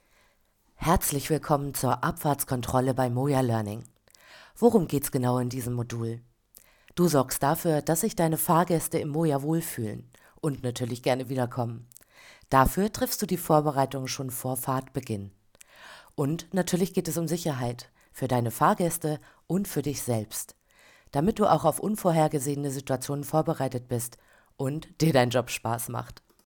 Vertonung E-learning Modul